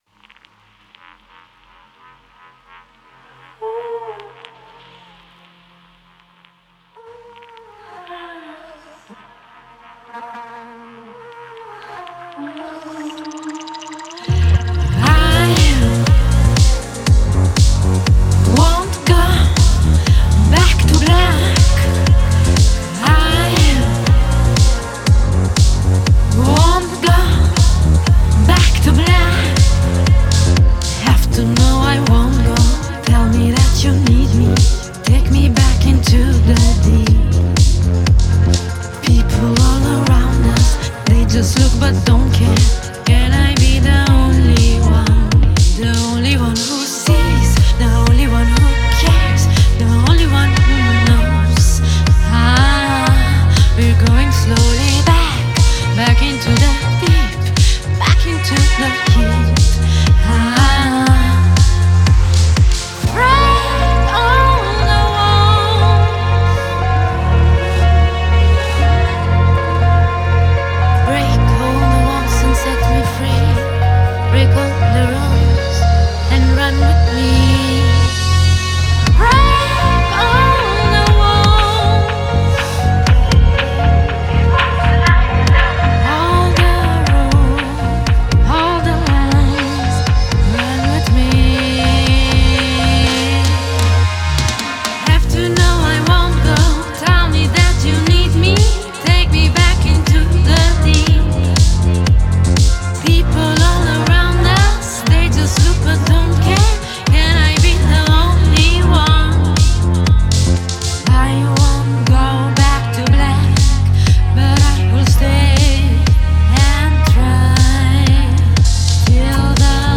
это трек в жанре поп с элементами R&B